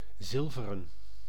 Ääntäminen
Ääntäminen Tuntematon aksentti: IPA: [ˈsrɛbrnɨ] Haettu sana löytyi näillä lähdekielillä: puola Käännös Ääninäyte 1. zilver {n} 2. zilveren 3. zilverkleurig 4. zilverkleurige Suku: m .